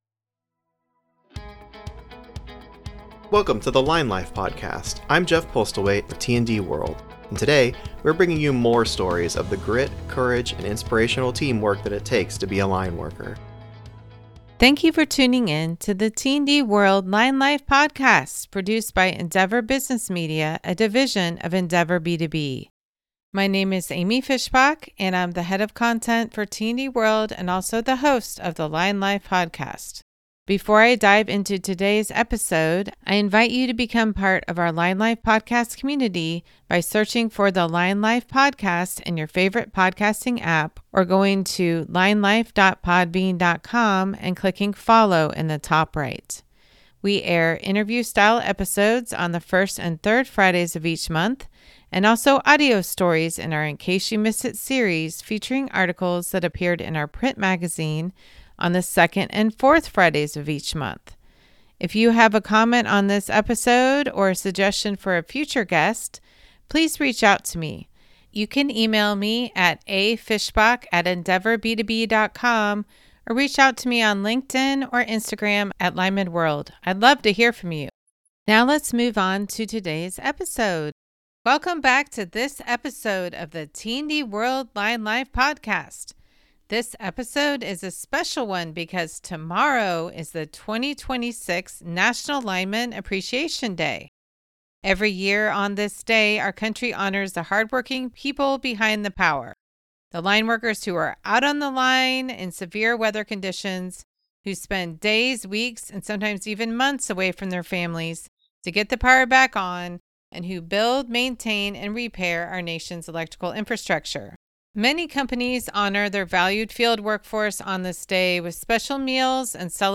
This special episode of the Line Life Podcast includes interviews with lineworkers about their most memorable storms and their lives working on the line.
Listeners will hear voices from across the industry — from apprentices to veterans — with stories of resilience, community response to disasters and the personal sacrifices line workers make to restore power.